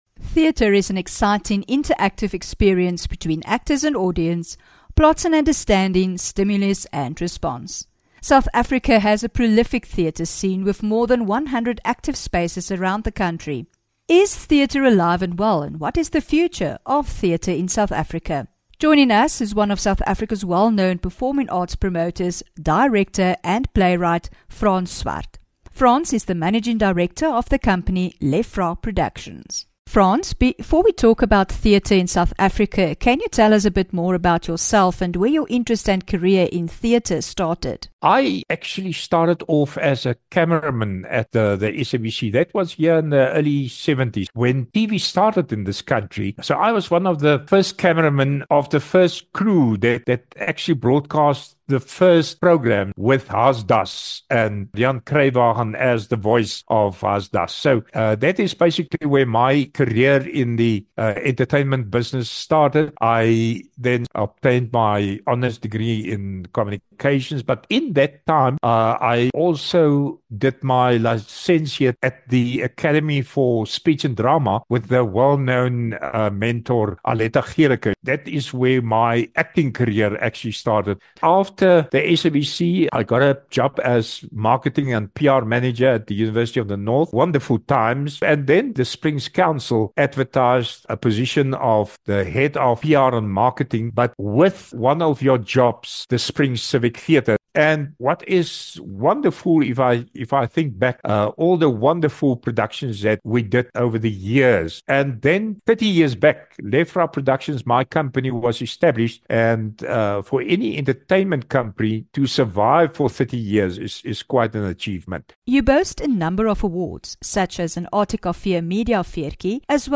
12 Mar INTERVIEW